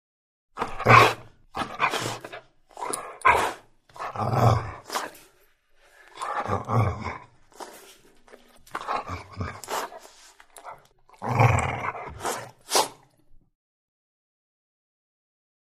DogGoldenLabBreath AT021701
Dog, Golden Lab; Breathes, Snorts And Snarls. [close Stereo],